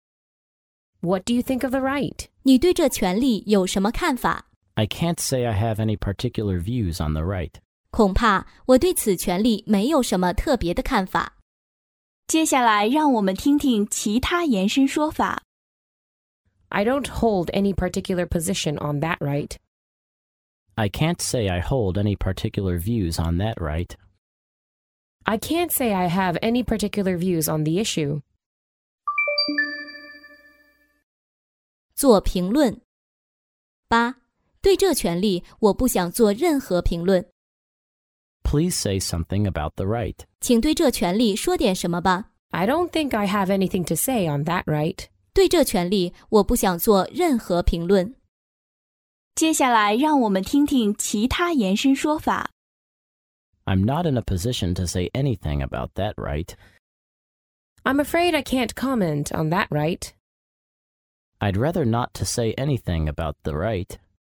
在线英语听力室法律英语就该这么说 第59期:恐怕我对此权利没有什么特别的看法的听力文件下载,《法律英语就该这么说》栏目收录各种特定情境中的常用法律英语。真人发音的朗读版帮助网友熟读熟记，在工作中举一反三，游刃有余。